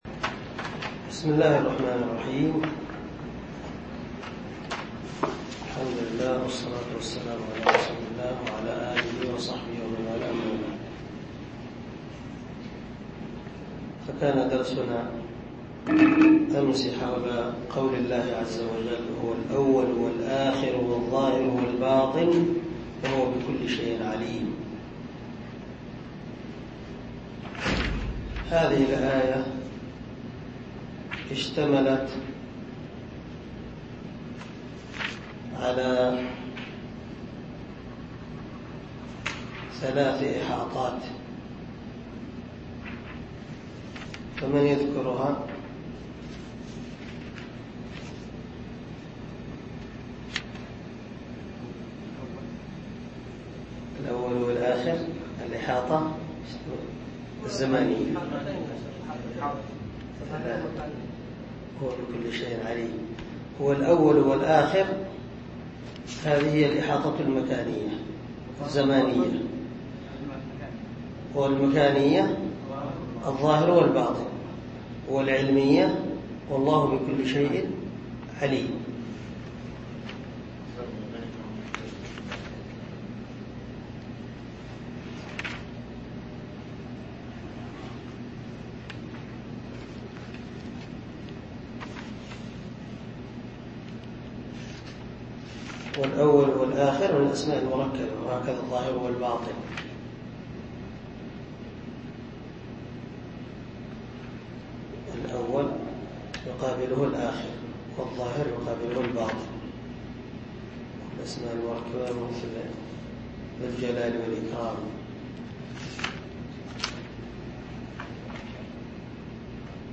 عنوان الدرس: الدرس السابع عشر
دار الحديث- المَحاوِلة- الصبيحة.